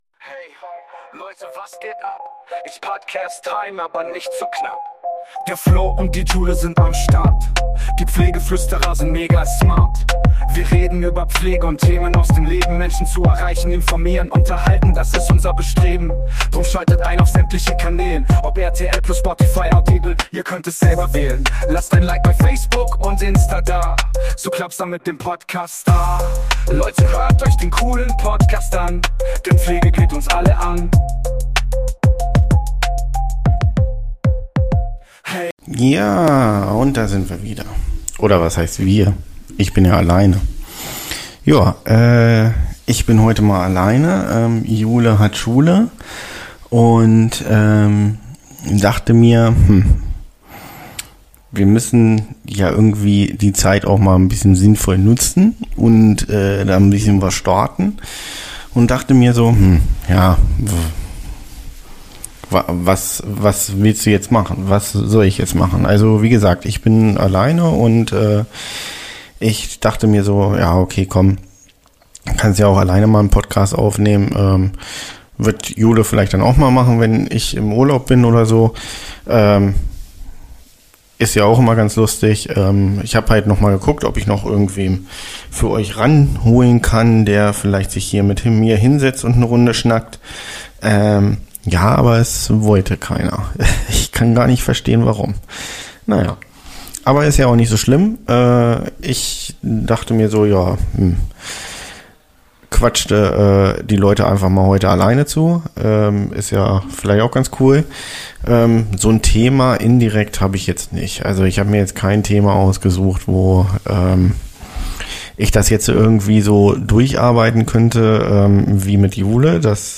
Heute bin ich mal alleine unterwegs.